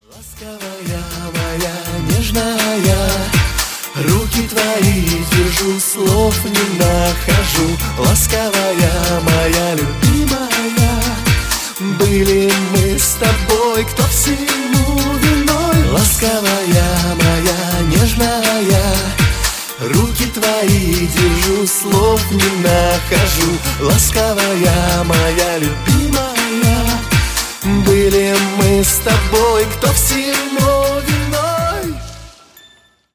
• Качество: 128, Stereo
поп
мужской вокал